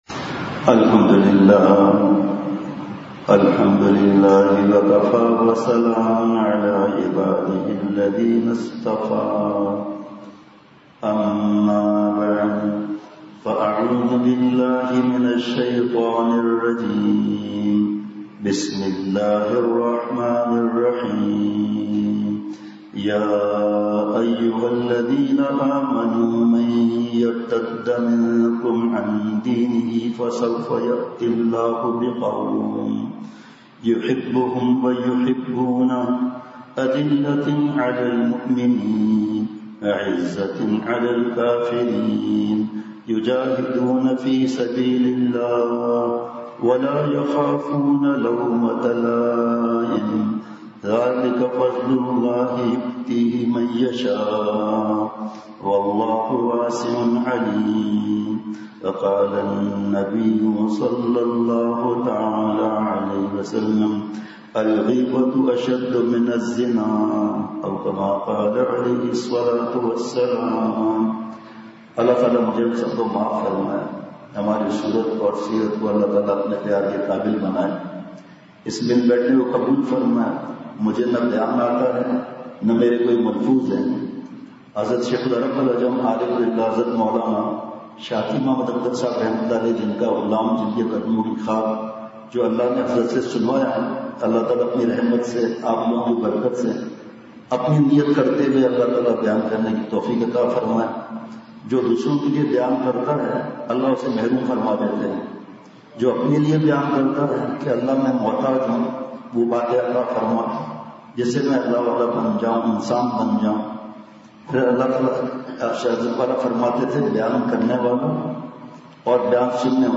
اصلاحی مجلس کی جھلکیاں بمقام :۔جامعہ امداد العلوم مسجد درویش صدر پشاور